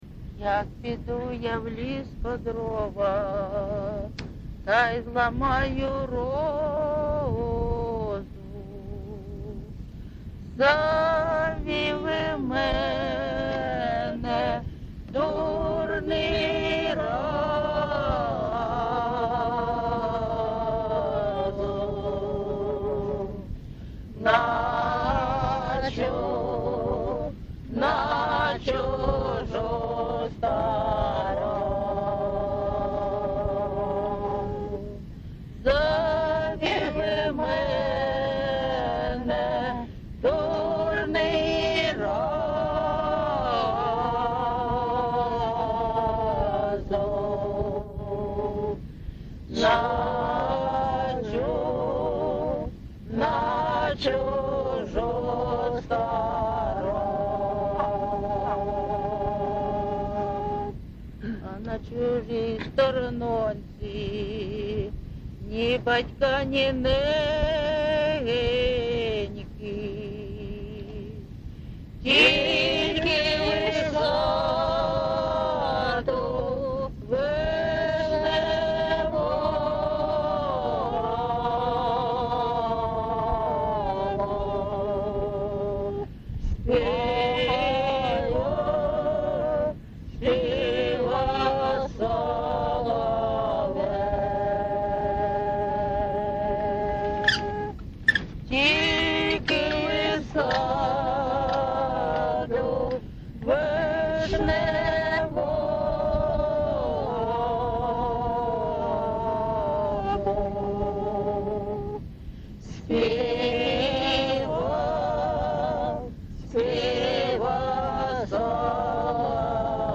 ЖанрПісні з особистого та родинного життя
МотивЧужина, Журба, туга
Місце записус. Богородичне, Словʼянський район, Донецька обл., Україна, Слобожанщина